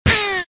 damageTaken.ogg